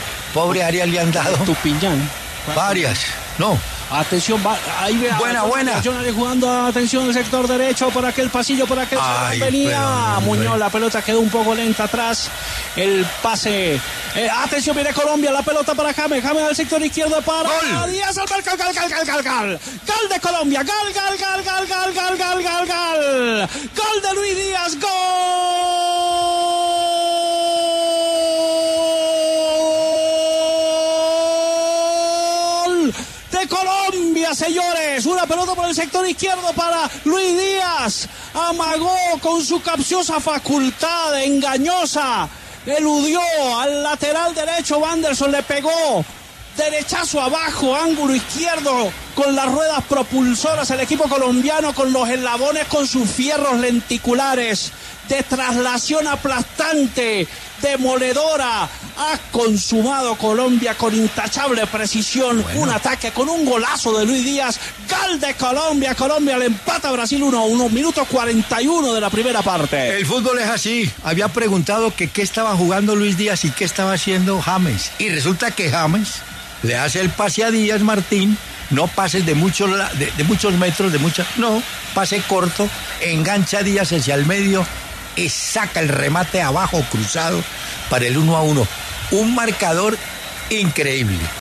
Martín De Francisco narró con su inconfundible “gal” la anotación de Luis Díaz ante Brasil por Eliminatorias.